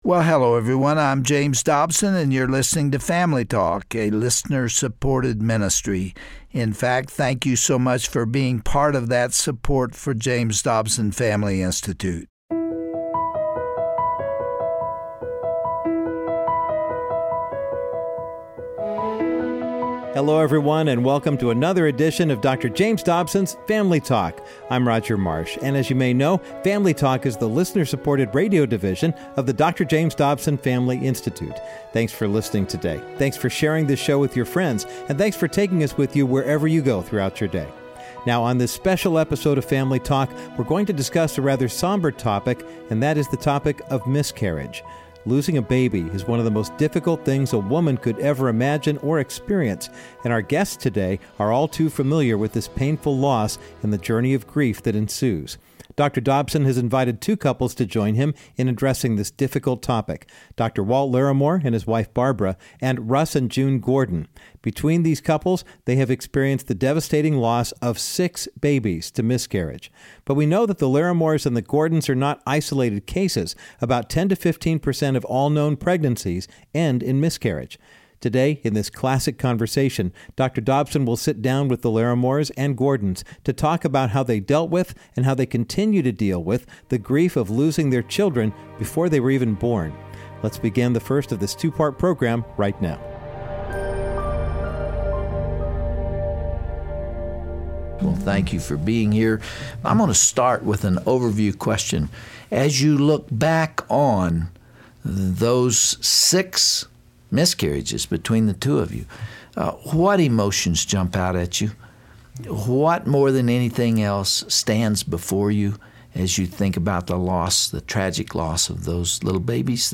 They share with host Dr. James Dobson that couples should name the lost baby, figuratively bury their child, and allow themselves time to heal.